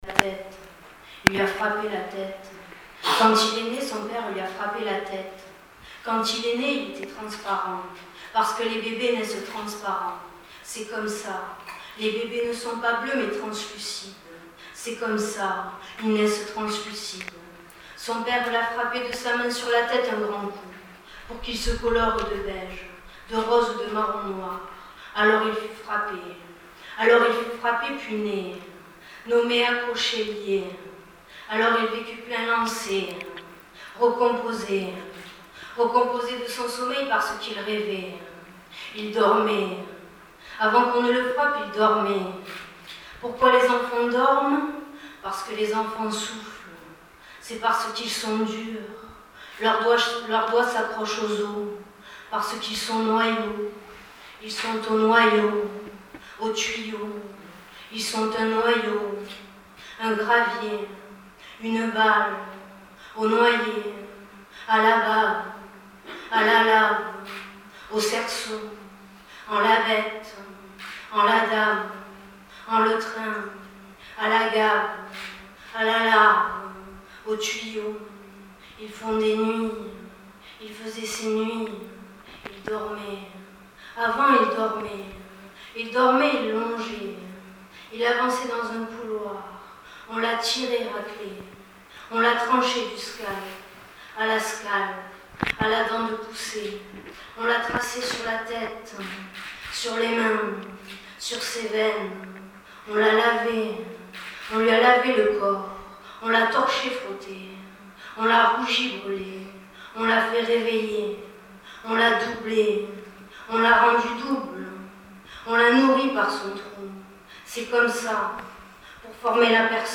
une jeune poétesse marseillaise qui a donné une lecture en rythme qui a dérouté certains des auditeurs qui pensaient que cette lecture avait bénéficié d’un traitement électronique de la voix. Extrait de sa lecture :